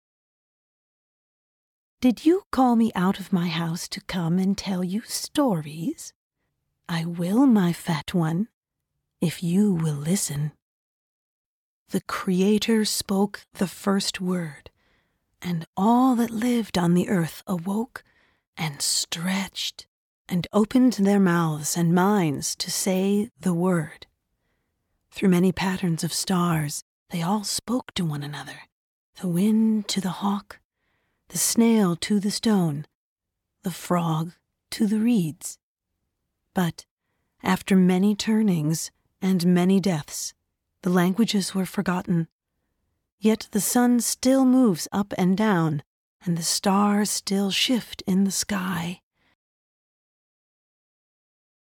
Conversational, Character; a Full Cast Audiobook of The Goose Girl by Shannon Hale, the Aunt